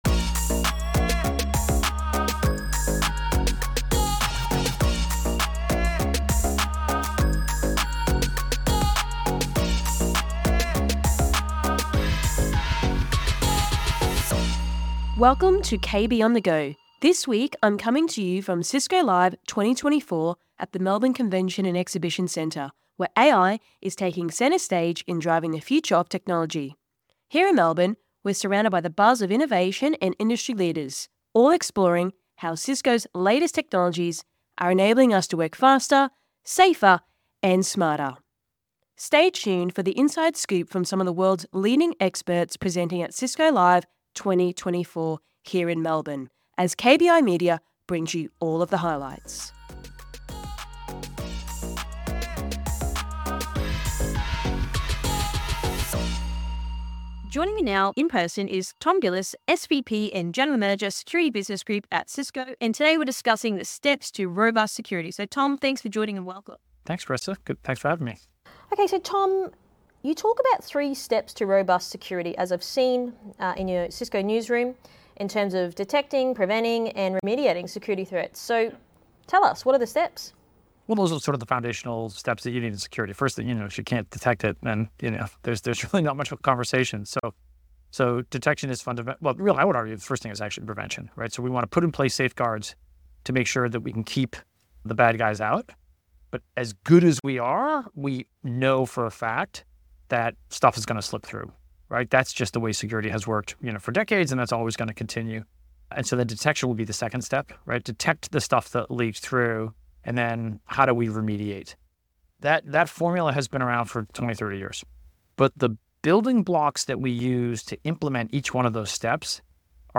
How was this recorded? From Cisco Live 2024 Melbourne